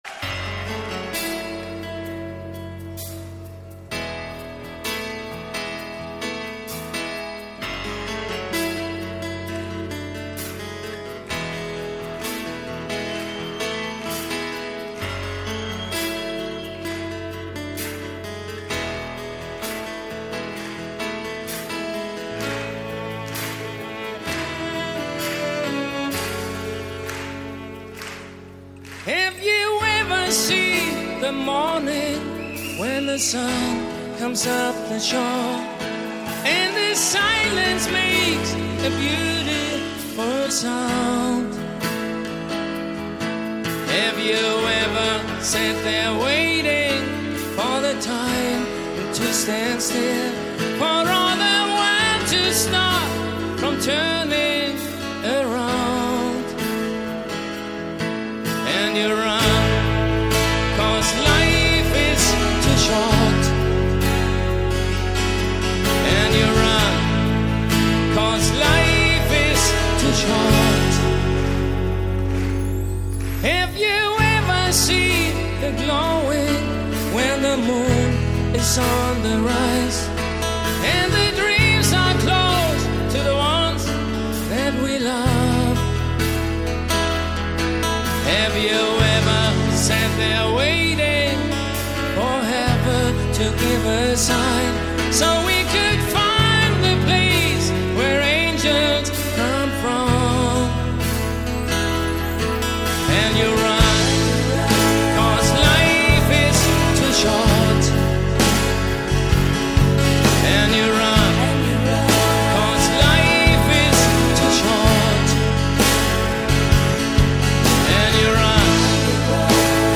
Жанр: Acoustic